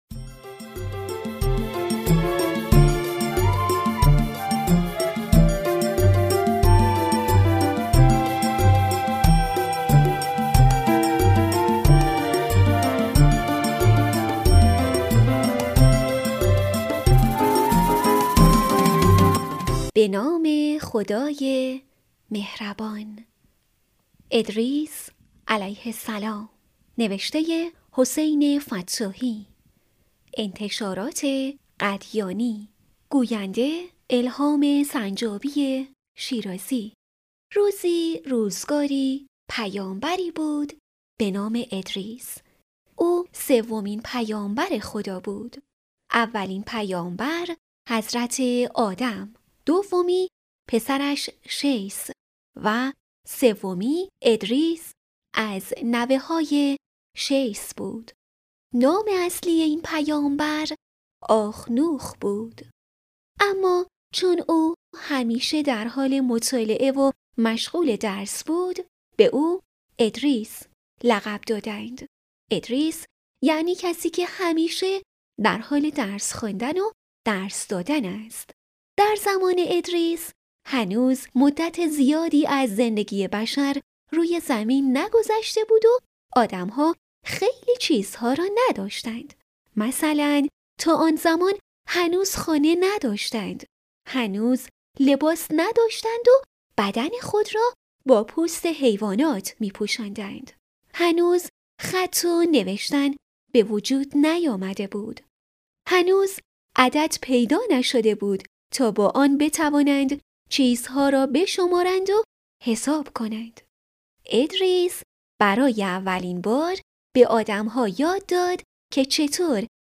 کتاب صوتی «پیامبران و قصه‌هایشان»